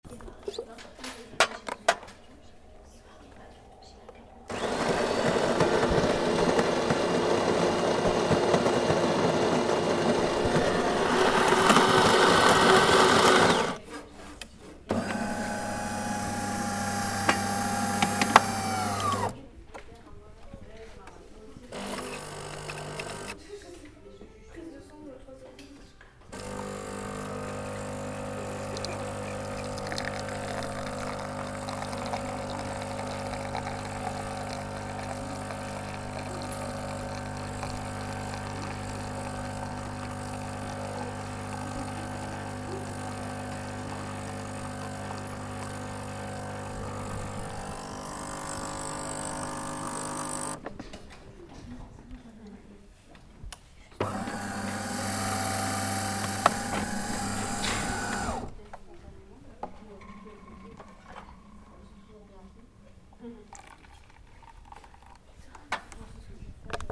AMBIANCE MACHINE A CAFE AU CINEMA